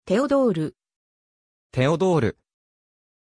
Pronuncia di Teodor
pronunciation-teodor-ja.mp3